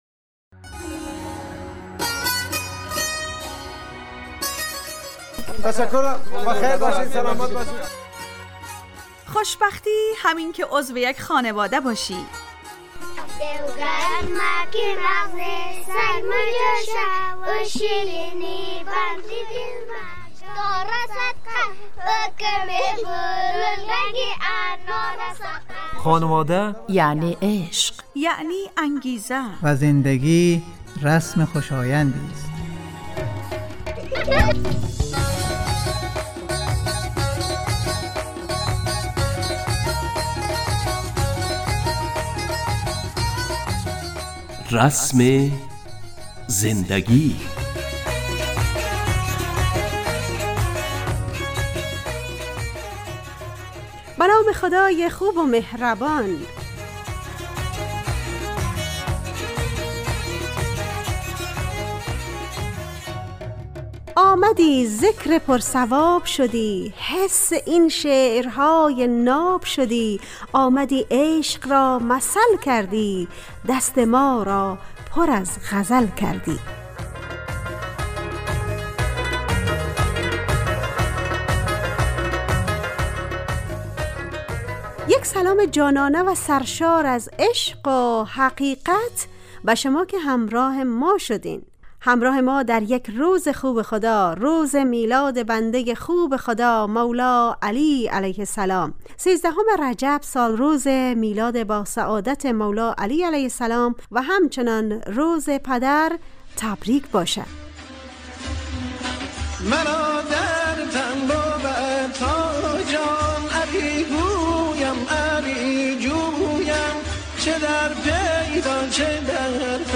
رسم زندگی_ برنامه خانواده رادیو دری